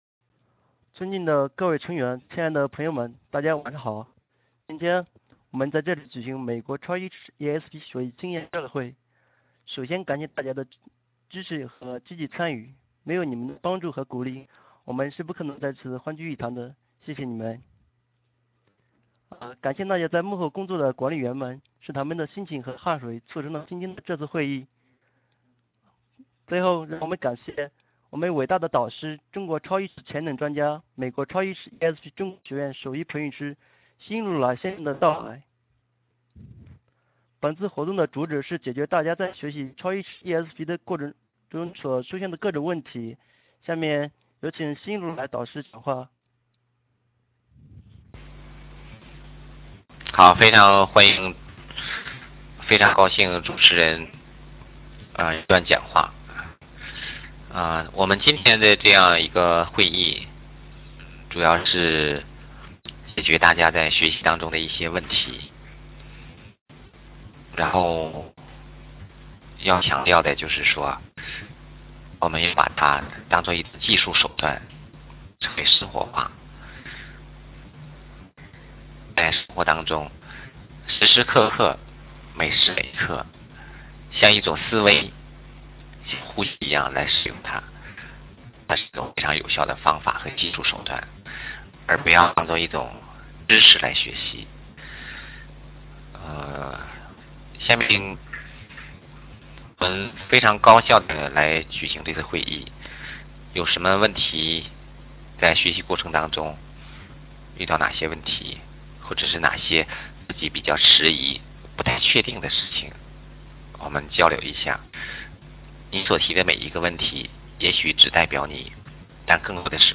超意识ESP音频答疑及爱的振动训练(5月22日) - 美国超意识ESP训练